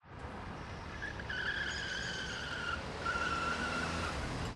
CSC-18-086-GV - Carro Derrapando em Rotatoria.wav